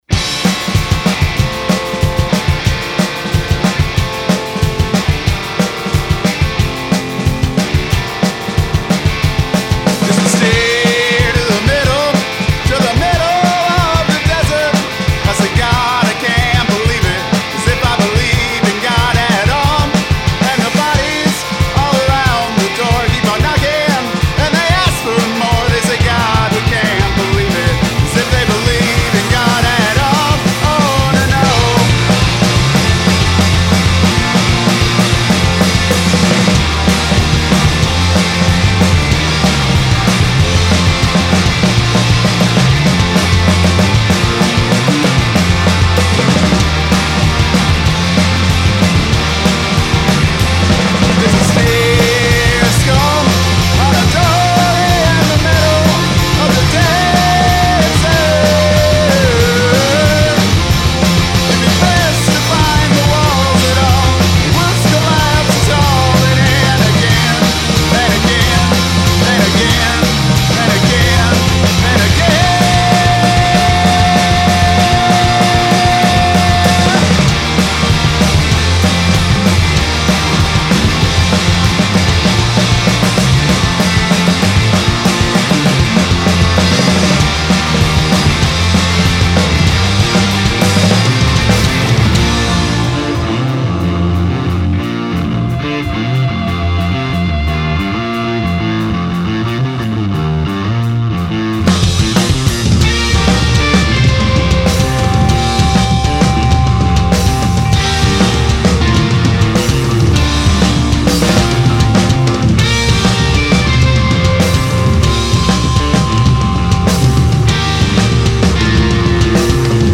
Guitars, Vocals, Keys
Drums
Bass
Cello
Trombone